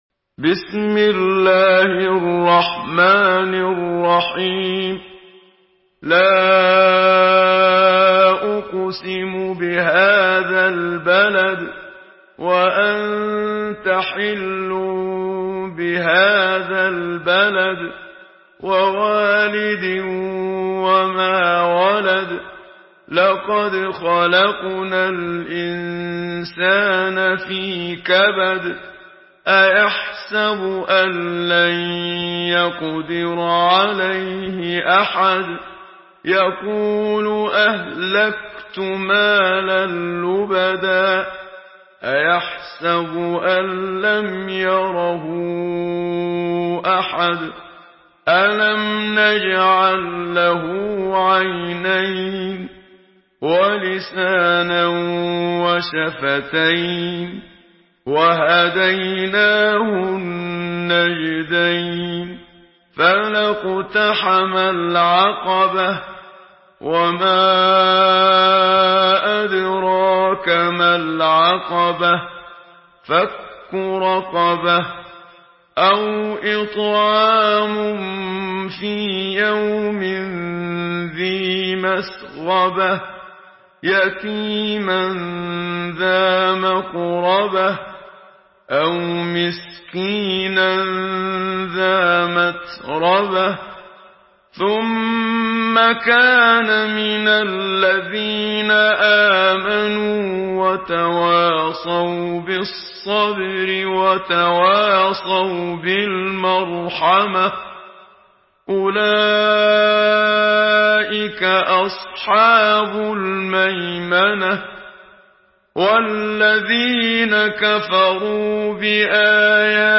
Surah Beled MP3 by Muhammad Siddiq Minshawi in Hafs An Asim narration.
Murattal Hafs An Asim